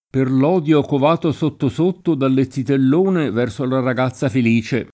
per l 0dLo kov#to S1tto S1tto dalle ZZitell1ne v$rSo la rag#ZZa fele] (Palazzeschi) — cfr. zita